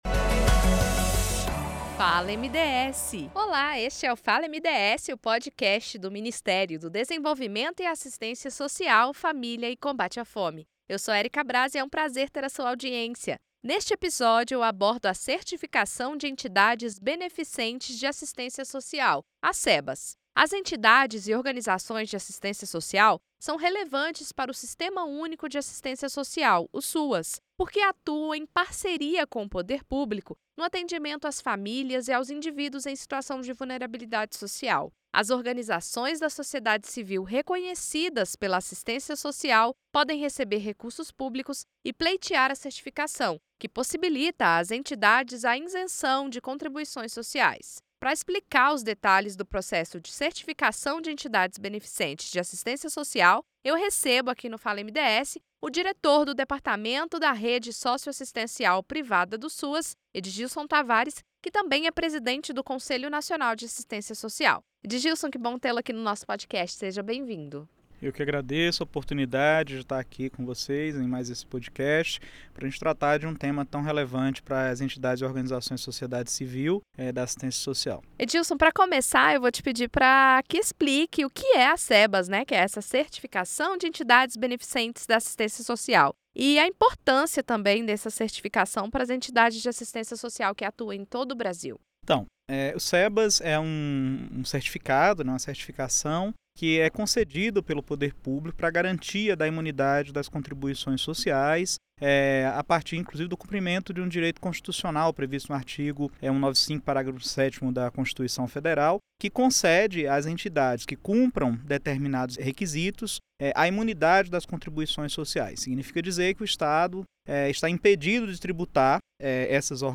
O Fala MDS desta sexta-feira (31.01) é uma reexibição do podcast Me Conta, Brasil, da Secretaria de Comunicação do Governo Federal. As convidadas são a secretária Nacional de Renda de Cidadania, Eliane Aquino, e a secretária de Gestão da Informação e Cadastro Único, Letícia Bartholo. As duas representantes do Ministério do Desenvolvimento e Assistência Social, Família e Combate à Fome (MDS) falam sobre o Programa Bolsa Família e o Cadastro Único.